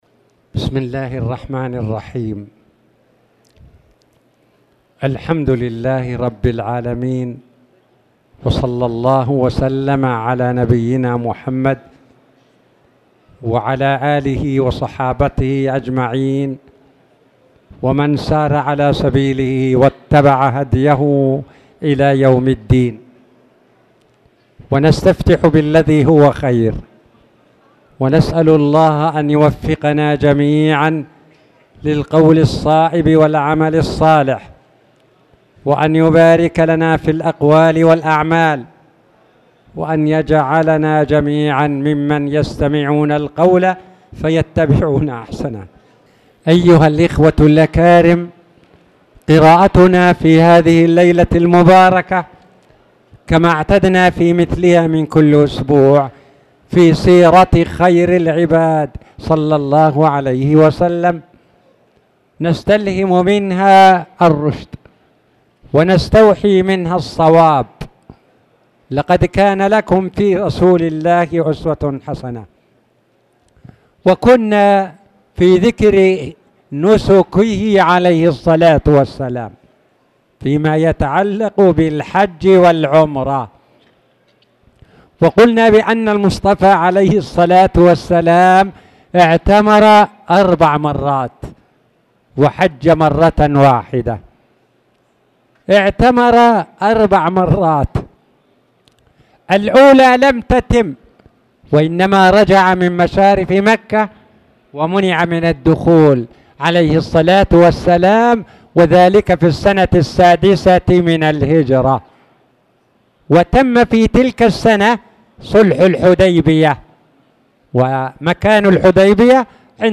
تاريخ النشر ٢٧ ذو الحجة ١٤٣٧ هـ المكان: المسجد الحرام الشيخ